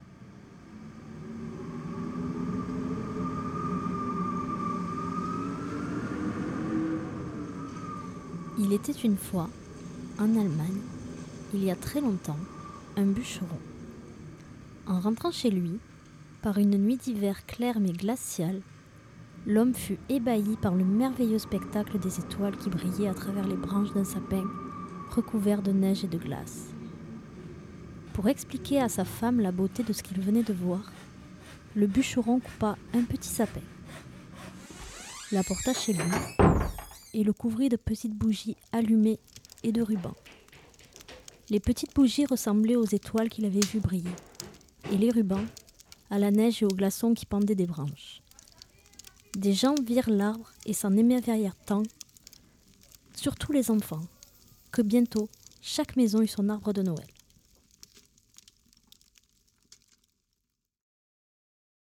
Les lectures de Noël, par le personnel du lycée
Le 22 décembre 2017, les personnels de l’établissement ont été invités à une découverte conviviale de l’outil radio et du studio du site de Lavacant. Entre autres activités, des textes sur le thème de Noël étaient proposés pour des lectures à belles et douces voix !